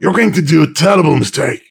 panelopen04.ogg